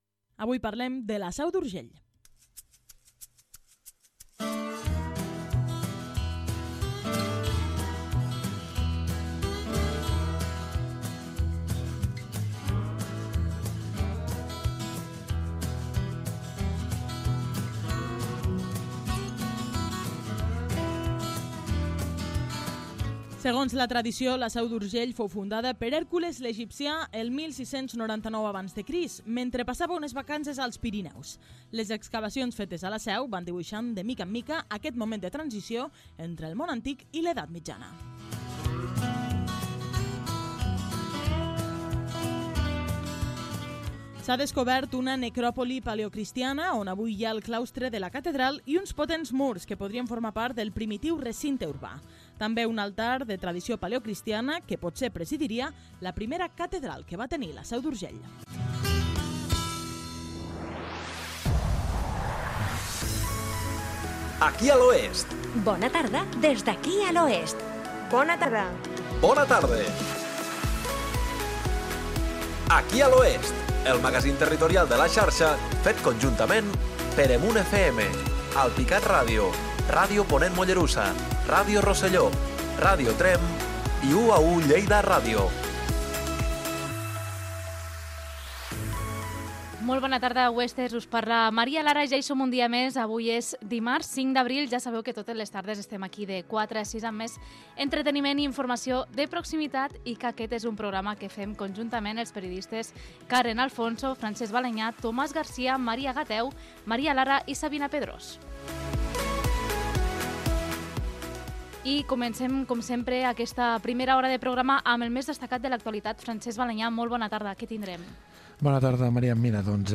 Info-entreteniment
Magazín territorial emès per EMUN FM, Alpicat Ràdio, Ràdio Ponent Mollerussa, Ràdio Roselló, Ràdio Tremp i UA1 Lleida Ràdio.